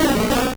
Cri d'Akwakwak dans Pokémon Or et Argent.